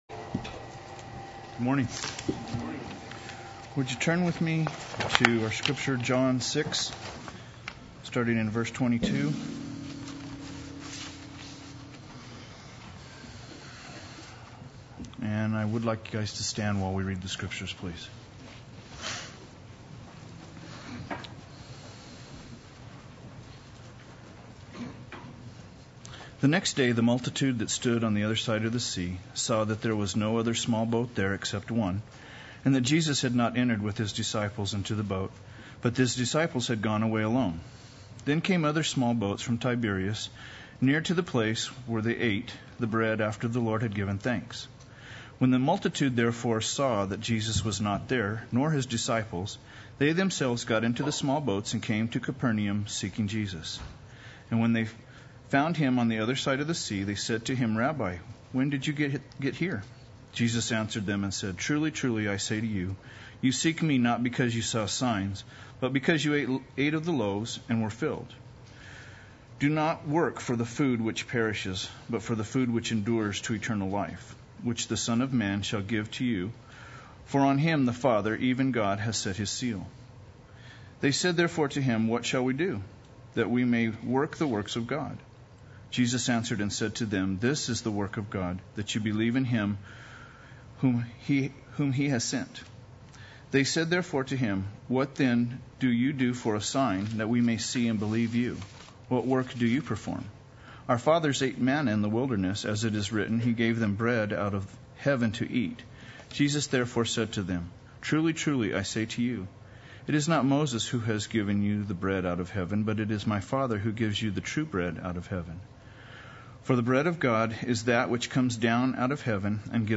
Play Sermon Get HCF Teaching Automatically.
I Am the Bread of Life Sunday Worship